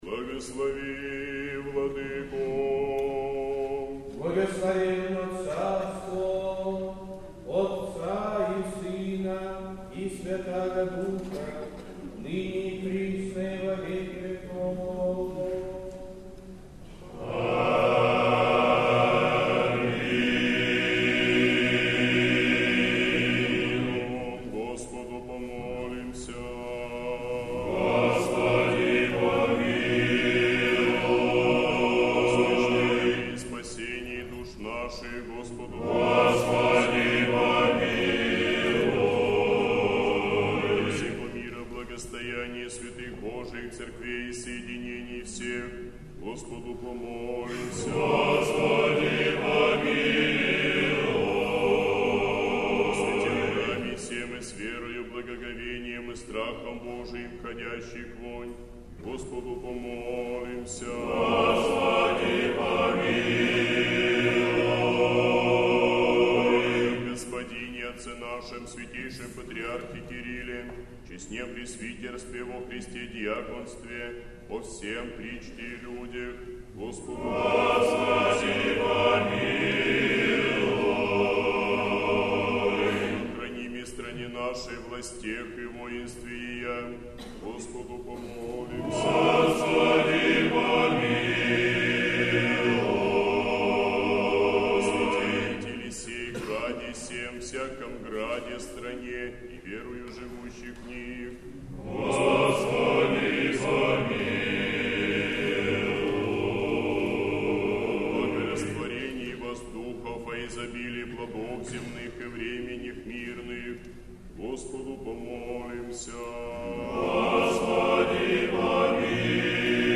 Божественная литургия. Хор Сретенского монастыря.
Божественная литургия в Сретенском монастыре в Неделю о блудном сыне